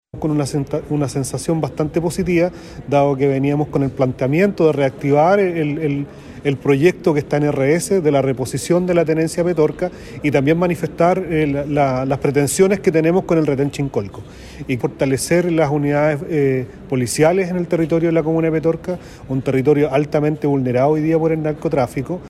Por otro lado, el alcalde de Petorca, Ignacio Villalobos, mencionó que se hace necesario agilizar los trabajos para volver a entregar seguridad a la población, precisando que se debe combatir el crimen organizado.
cu-monsalve-en-petorca-alcalde.mp3